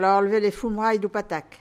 Localisation Sainte-Foy
Catégorie Locution